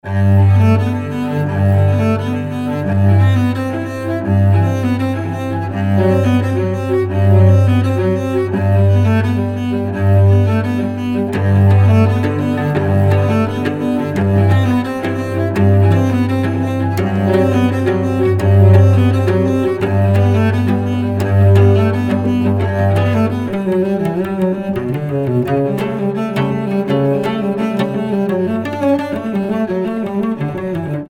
• Качество: 320, Stereo
спокойные
без слов
инструментальные
виолончель
лёгкая музыка
Красивая современная классическая музыка.